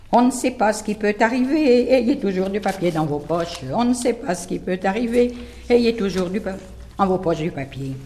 branle : courante, maraîchine
Pièce musicale inédite